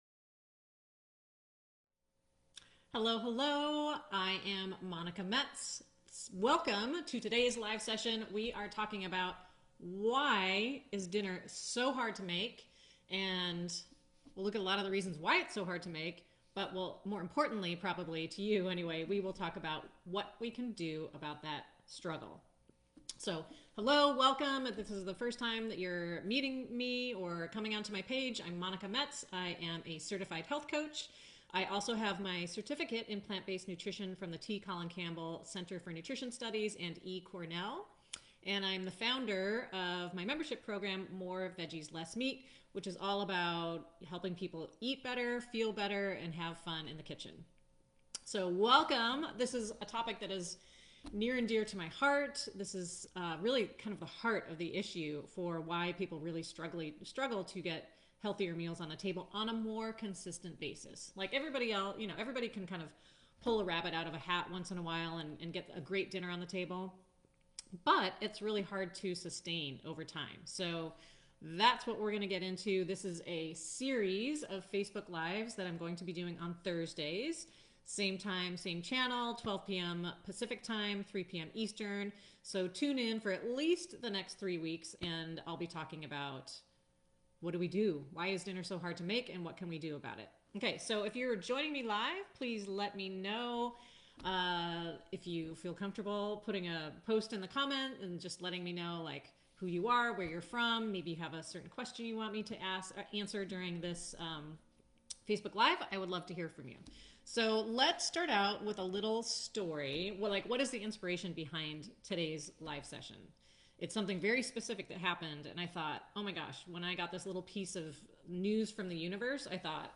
Facebook Live Series